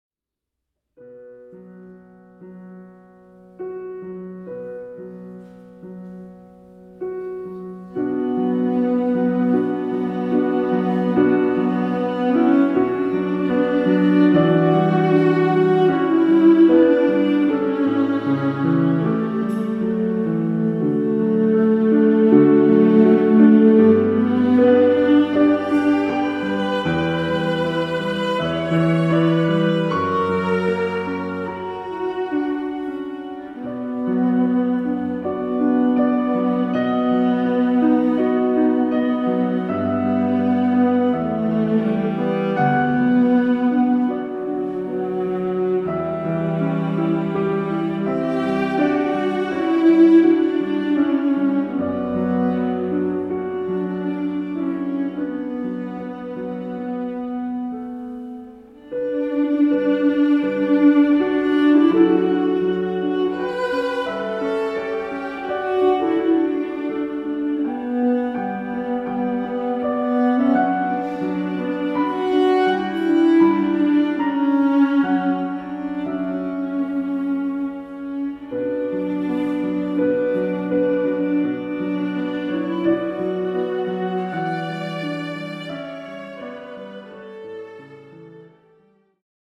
(in der Trauerhalle)
klassische Musik